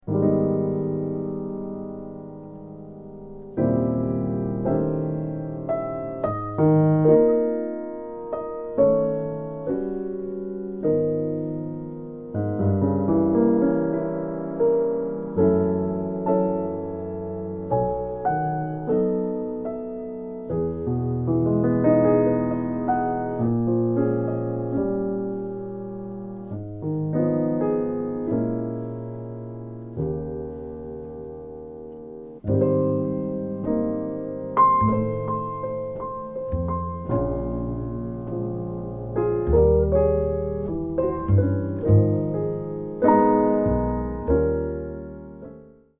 as a ballad.
flugelhorn